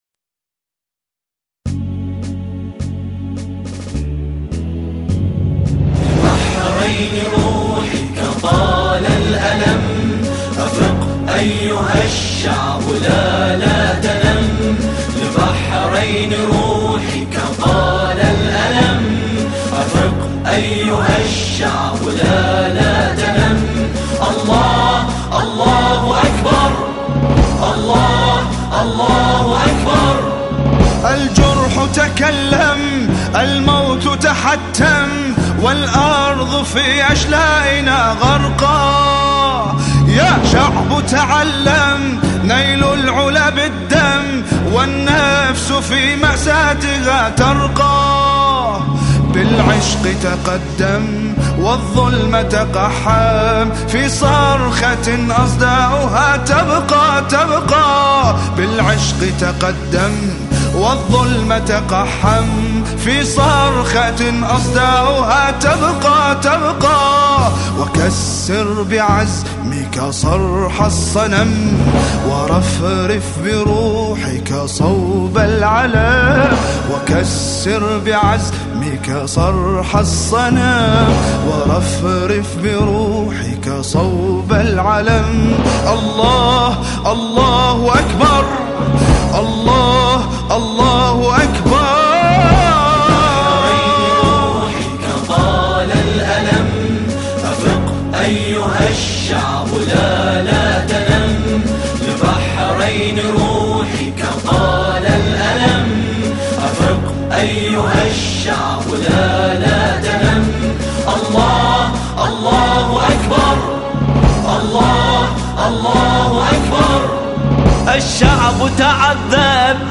أناشيد بحرينية نشيد